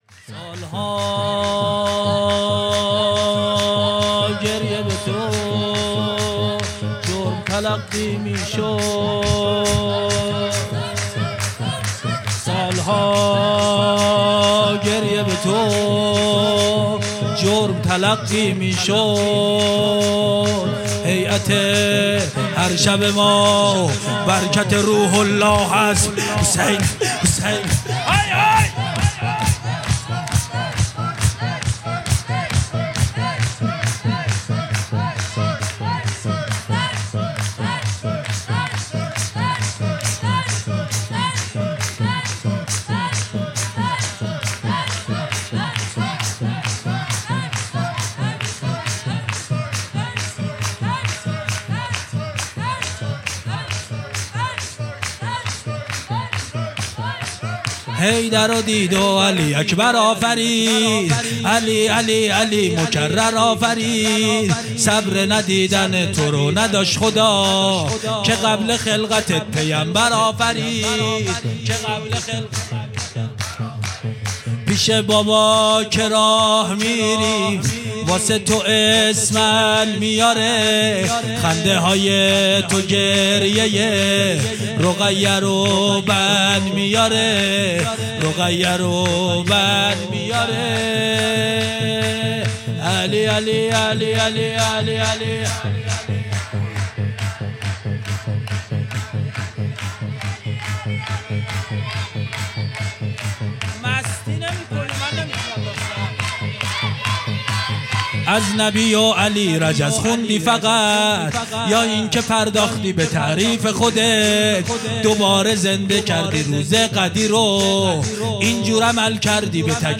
خیمه گاه - هیئت محبان الحسین علیه السلام مسگرآباد - سرود پایانی